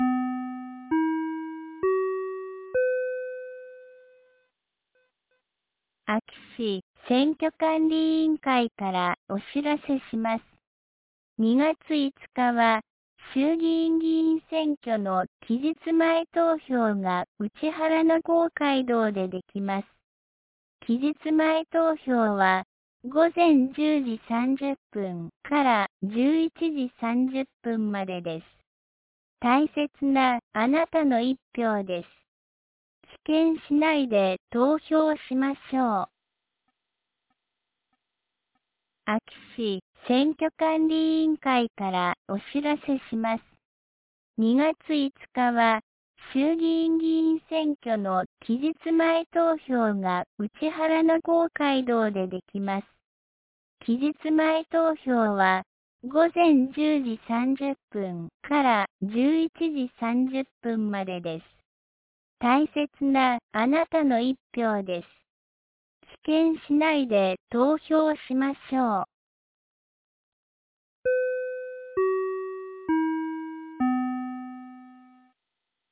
2026年02月04日 09時01分に、安芸市より井ノ口へ放送がありました。